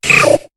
Cri de Héricendre dans Pokémon HOME.